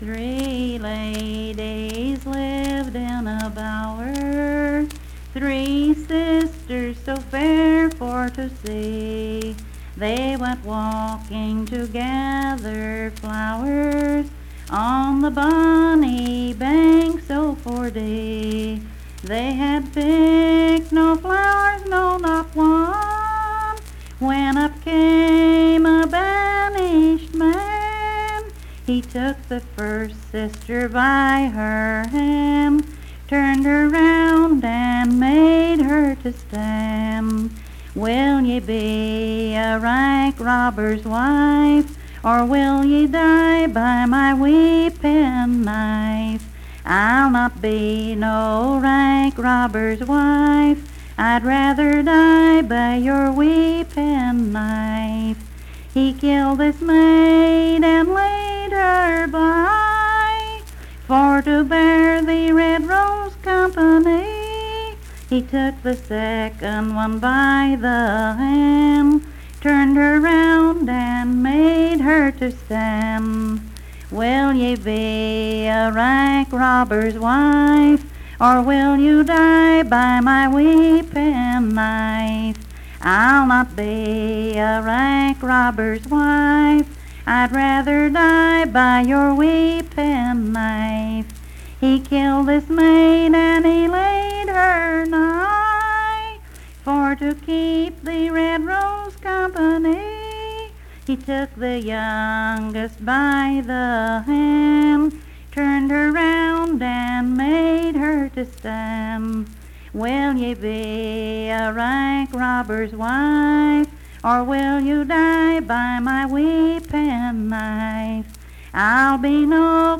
Unaccompanied vocal music
Verse-refrain 6(8).
Performed in Coalfax, Marion County, WV.
Voice (sung)